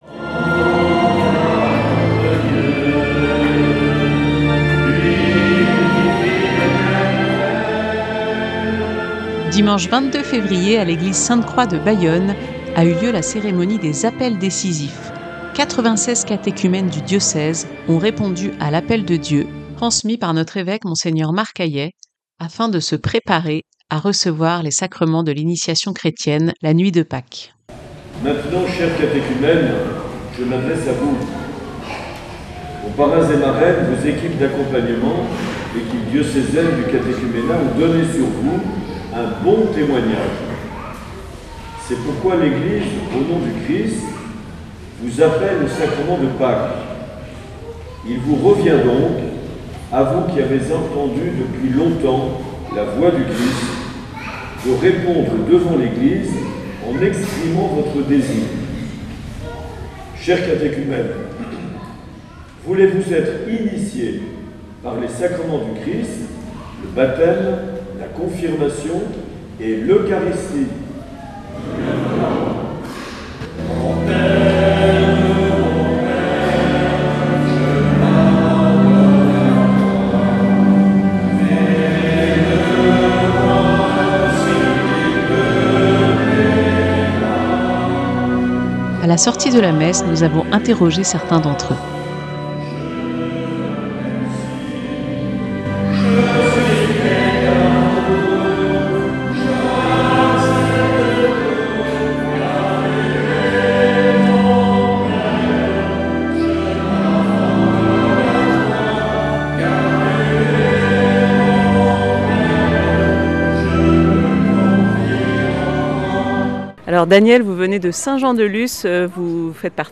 Dimanche 22 février, l’église Sainte-Croix de Bayonne a accueilli un moment fort de la vie diocésaine.
Interviews et reportages